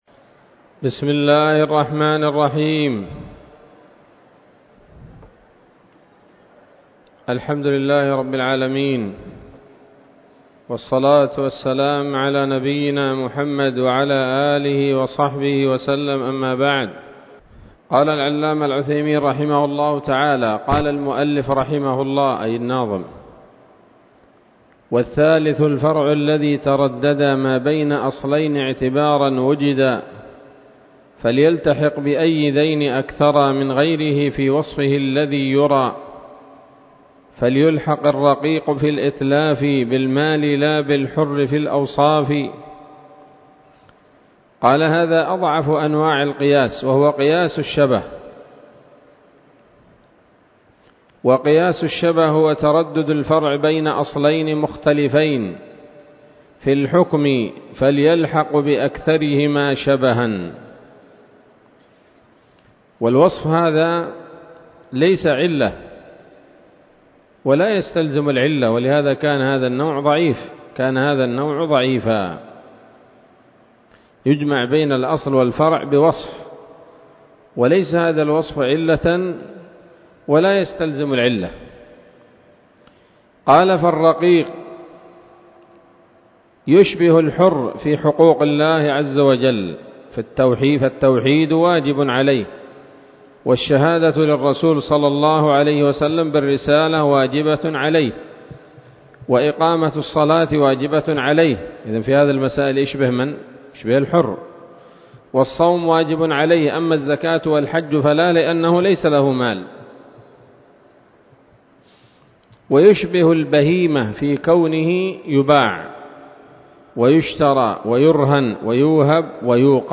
الدرس الثاني والستون من شرح نظم الورقات للعلامة العثيمين رحمه الله تعالى